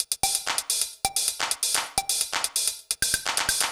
TEC Beat - Mix 5.wav